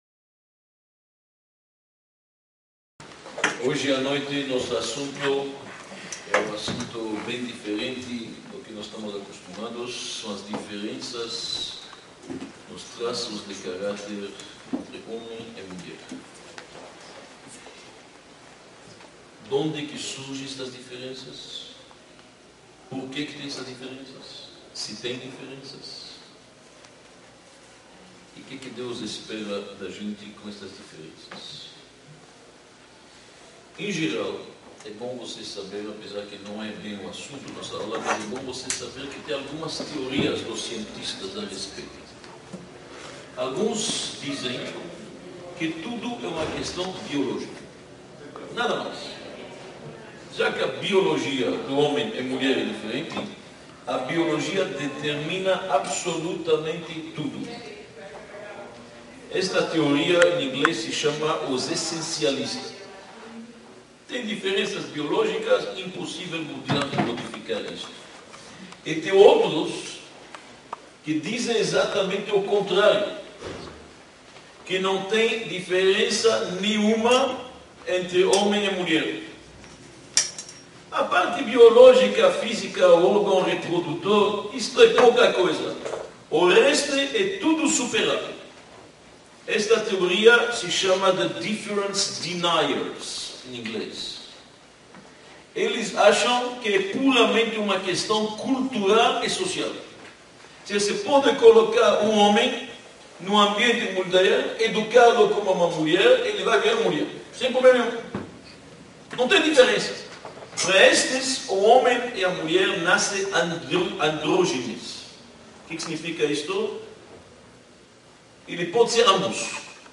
Palestra-Diferenças-Cósmicas-entre-Homens-e-Mulheres.mp3